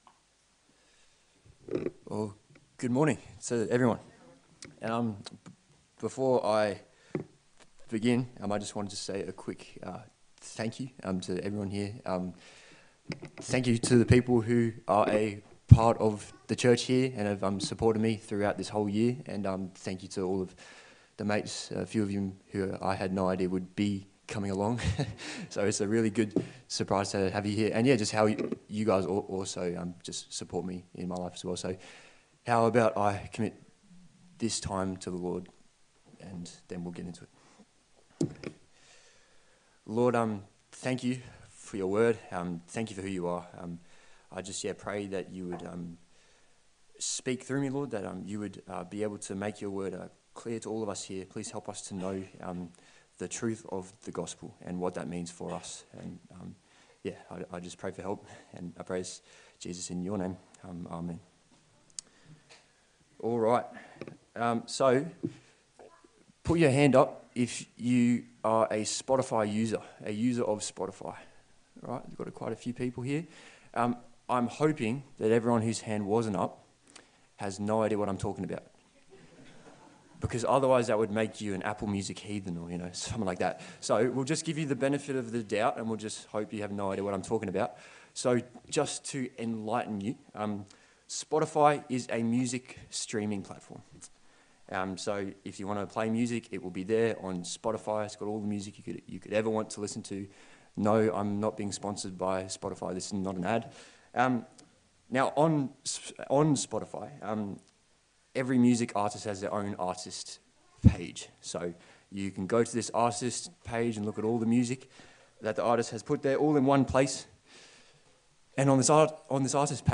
Service Type: Sunday Service A sermon in the series on the Gospel of Luke